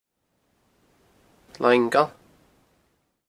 Gaelic name: Langail Name in Original Source: Langail English meaning: Long field Placename feature: Field Notes: In current usage, it is almost always rendered as Langwell, it is generally pronounced Line-gal by local Gaelic speakers. This is believed to be a Norse name.